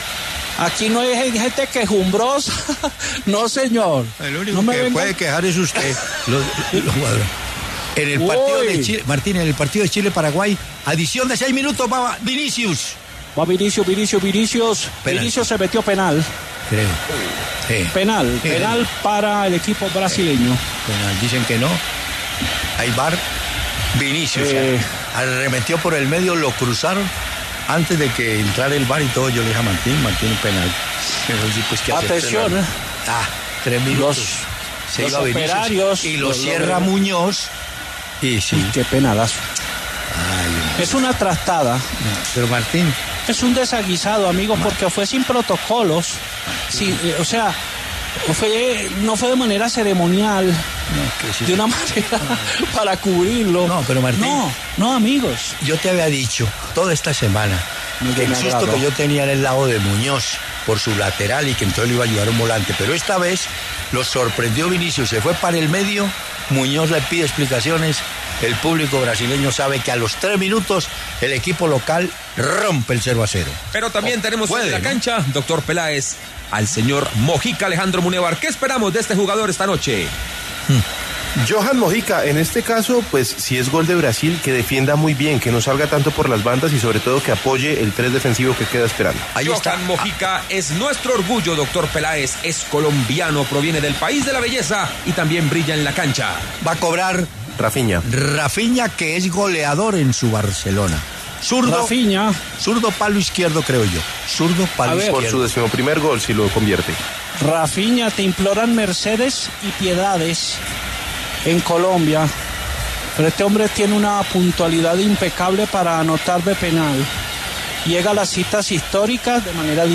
“Hay desazón”: Martín De Francisco en narración del gol de Raphinha ante Colombia
El reconocido comentarista deportivo Martín De Francisco reaccionó en vivo al gol ‘de camerino’ de Brasil ante Colombia en la jornada 13 de las Eliminatorias Sudamericanas.